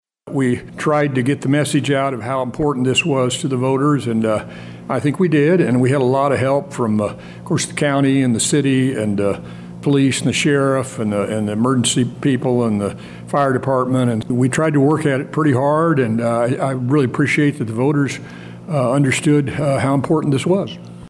Livingston County Presiding Commissioner Ed Douglas says he is pleased for the voter support.